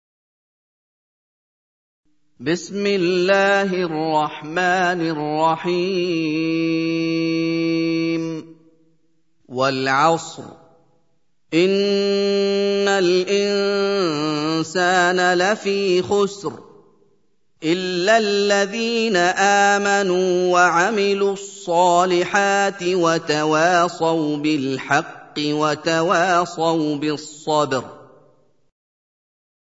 Surah Sequence تتابع السورة Download Surah حمّل السورة Reciting Murattalah Audio for 103. Surah Al-'Asr سورة العصر N.B *Surah Includes Al-Basmalah Reciters Sequents تتابع التلاوات Reciters Repeats تكرار التلاوات